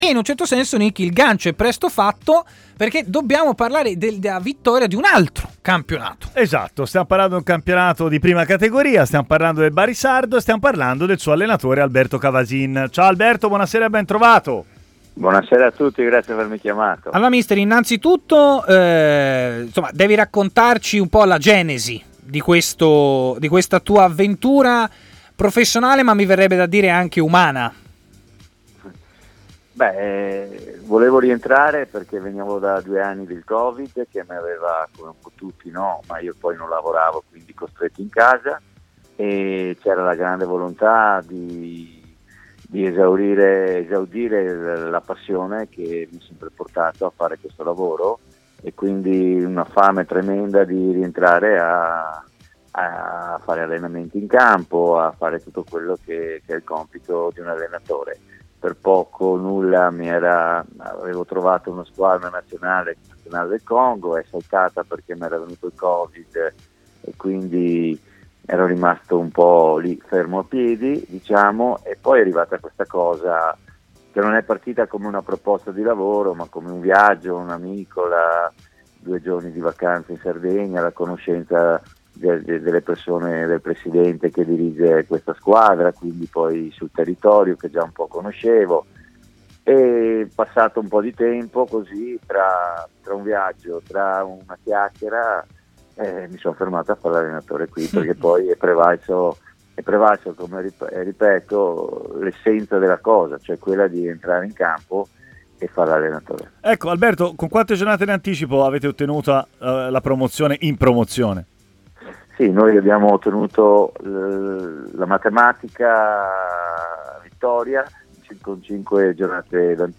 ha parlato a Stadio Aperto, trasmissione di TMW Radio